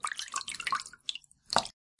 Water » Slap 005
描述：Some sounds I recorded on a very minimalist setup of a Tascam DR05 and a dream.
标签： Sea Slap Dripping aqua marine River wave Drip crash bloop Game Water Running blop Run pouring Wet pour Splash Lake Movie aquatic
声道立体声